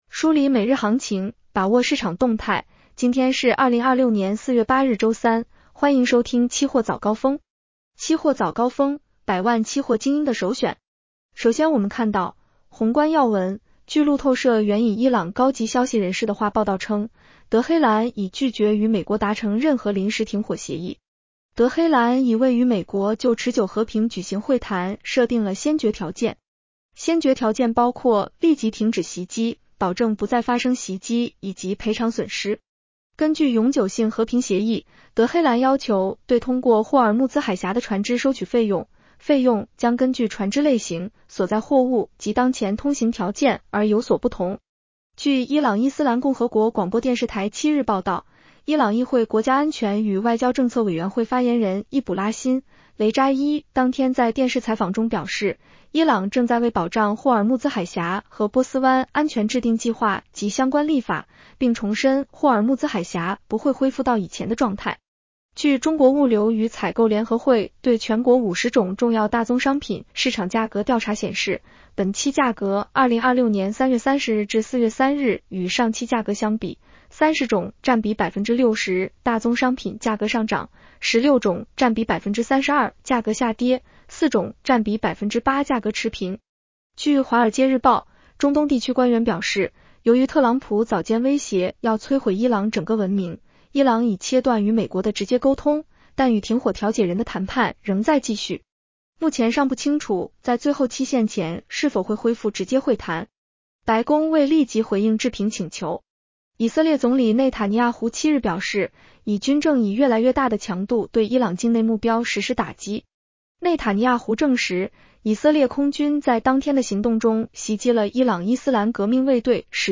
期货早高峰-音频版
期货早高峰-音频版 女声普通话版 下载mp3 热点导读 1.特朗普表示同意在两周内暂停对伊朗的轰炸和袭击。